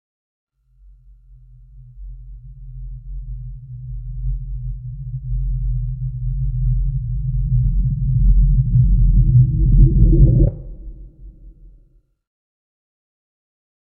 На этой странице собраны звуки черной дыры, преобразованные из электромагнитных и гравитационных волн.
Звук взрыва черной дыры